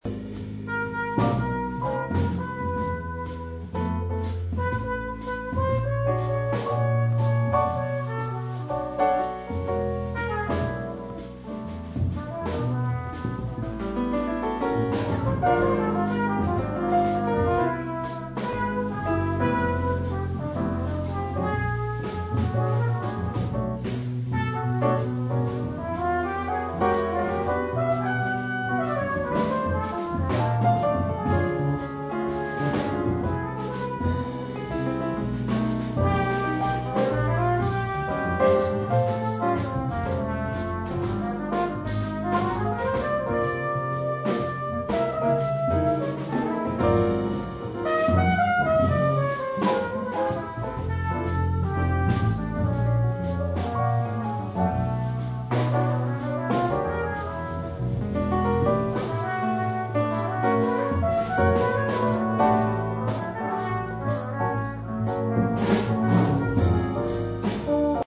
Sonoma State University Faculty Concert Cuts
jazz
Trumpet
Alto Sax
Flute
Trombone
Acoustic Bass
Drums
Guitar
Piano